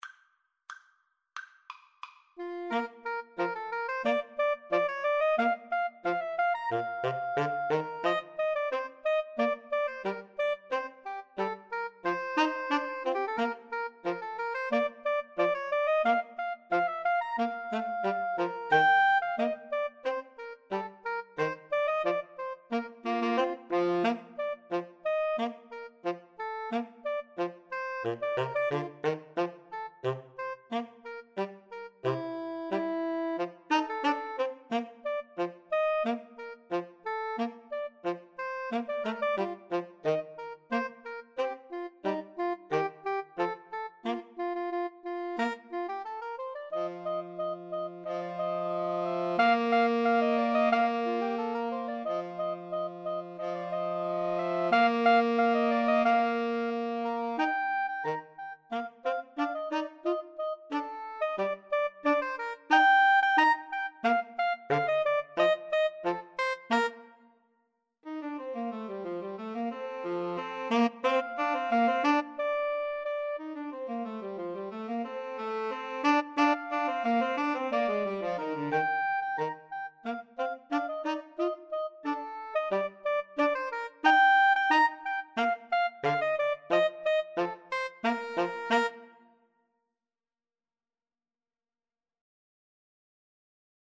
Bb major (Sounding Pitch) (View more Bb major Music for Woodwind Trio )